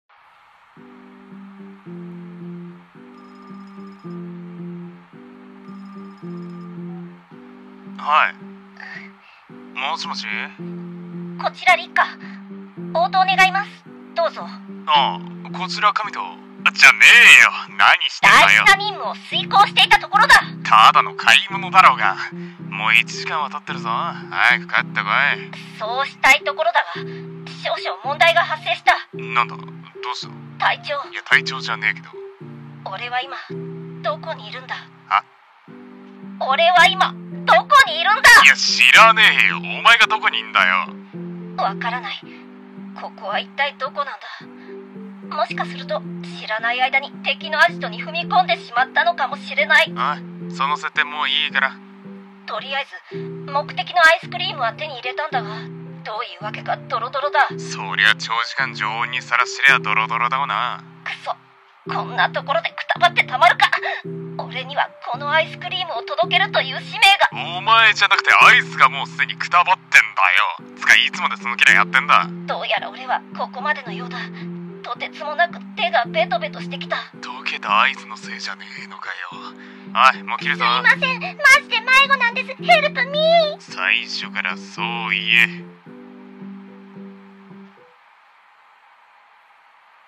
ギャグ声劇台本】方向音痴